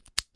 锁定声音 " 锁定2 插入钥匙3
描述：钥匙插入锁
Tag: 解锁 打开 关闭 关闭 采摘 钥匙 开锁 钥匙 打开门